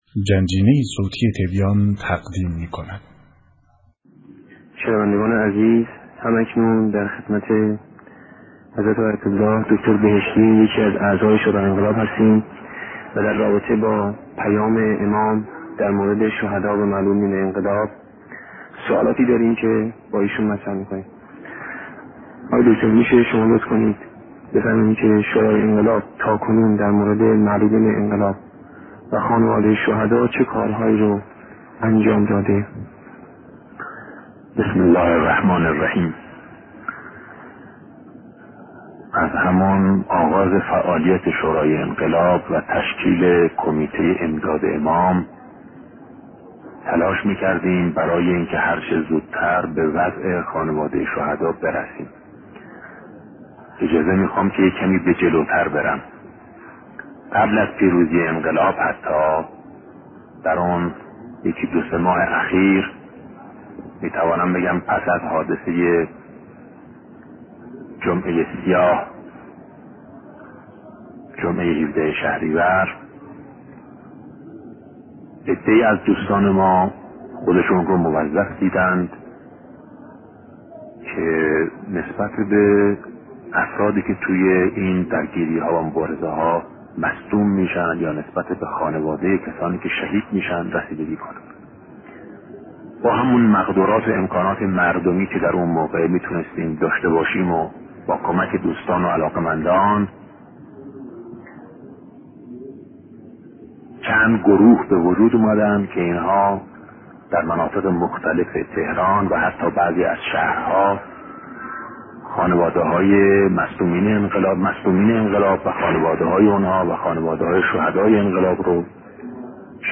سخنرانی شهید دکتر بهشتی- با موضوعیت اقدامات شورای انقلاب در قبال ایثارگران